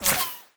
bullet_flyby_fast_15.wav